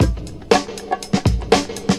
• まずは何もしていないリズム・ループ・・・